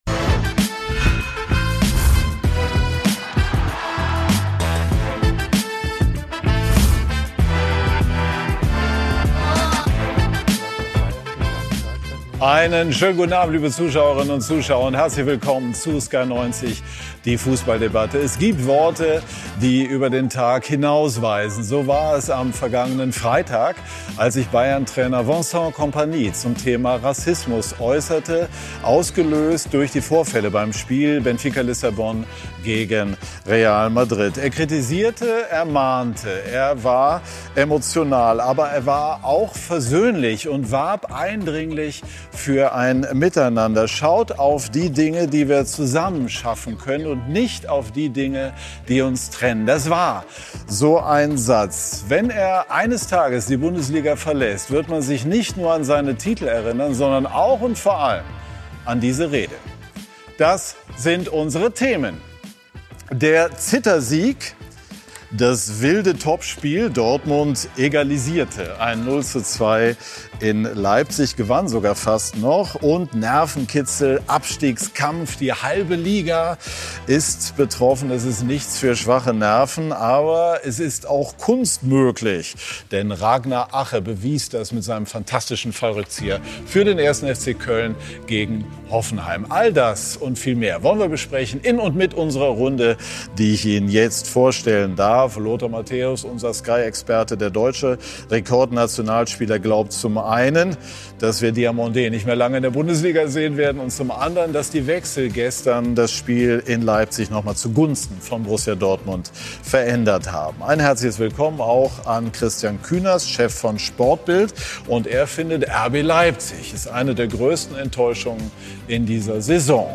Kontrovers, unterhaltsam, meinungsbildend – mit Sky90 präsentiert Sky den umfassendsten Fußball-Live-Talk Deutschlands.
Sky-Kommentator Lothar Matthäus: Sky-Experte und Weltmeister Mehr